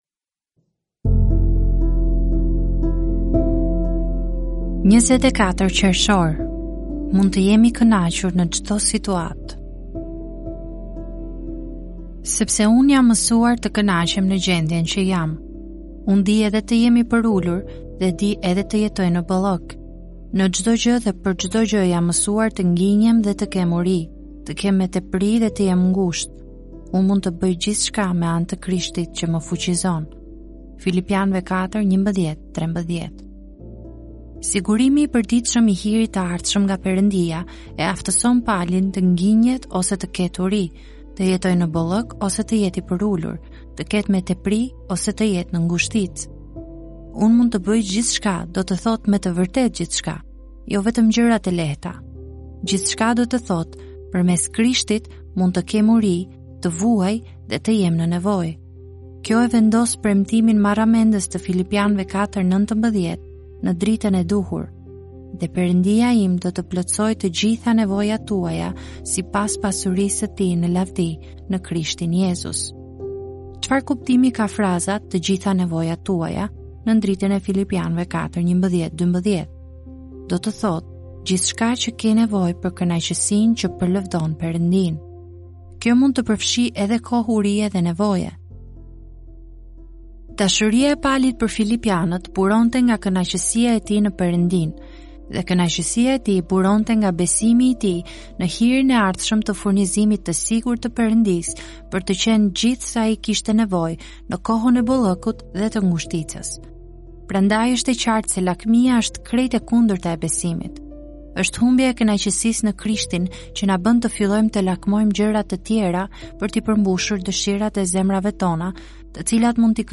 "Solid Joys" janë lexime devocionale të shkruara nga autori John Piper.